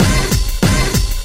Track 14 - Hit FX.wav